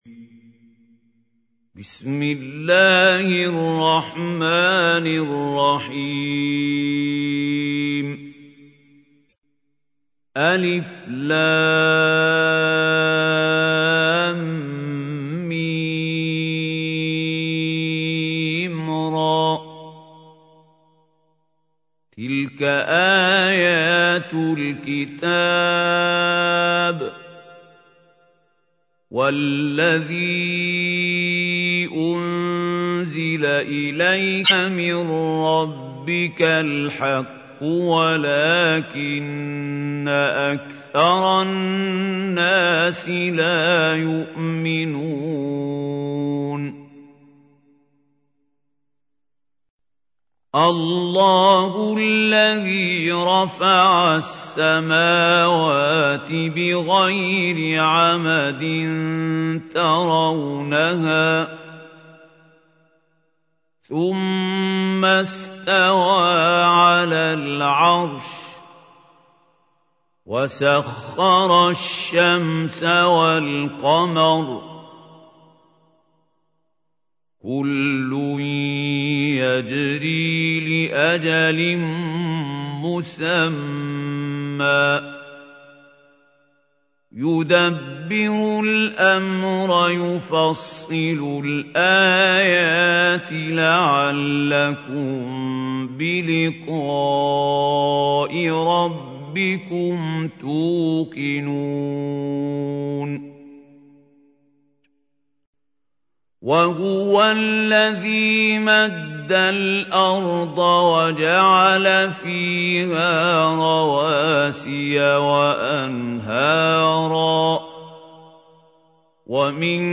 تحميل : 13. سورة الرعد / القارئ محمود خليل الحصري / القرآن الكريم / موقع يا حسين